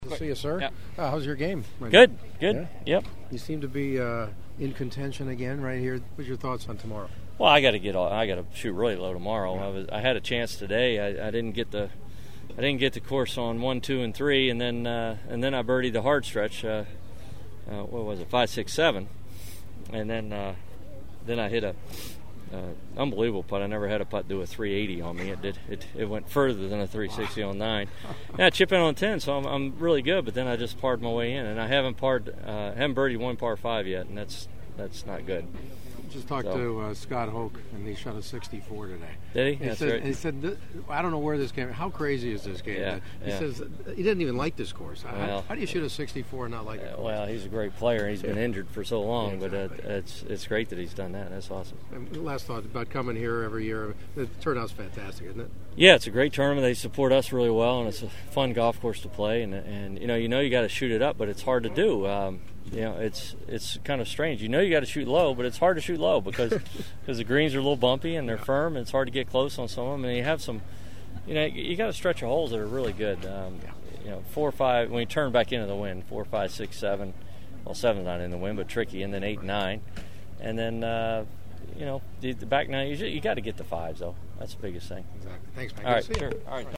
Hanging with my own age at the Champions Tour’s Toshiba Classic in Newport Beach